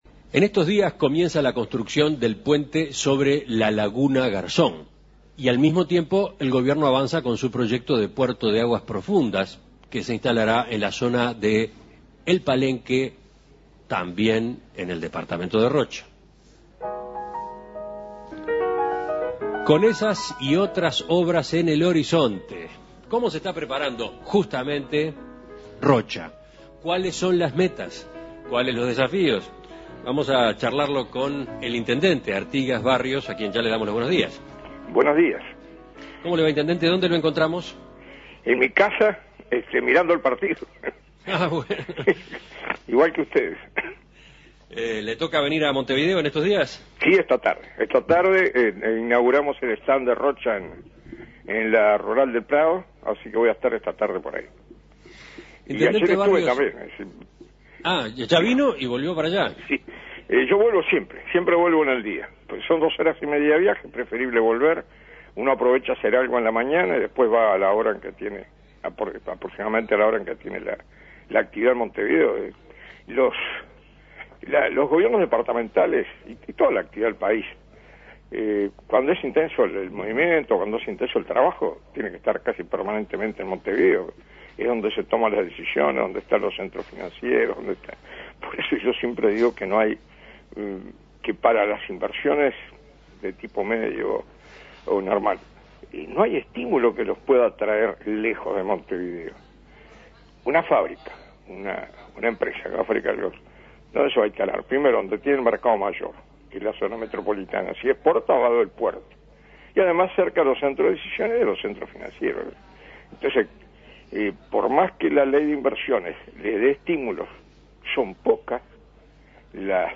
En Perspectiva entrevistó al intendente de Rocha, Artigas Barrios. El jefe comunal destacó el gran desafío que tiene por delante el departamento, y en particular el próximo Gobierno departamental.